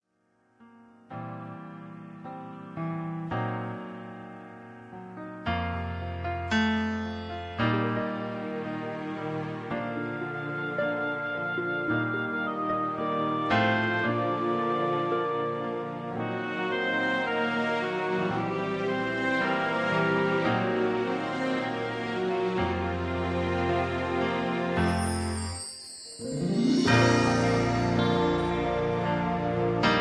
(Key-Bb-B) Karaoke MP3 Backing Tracks
Just Plain & Simply "GREAT MUSIC" (No Lyrics).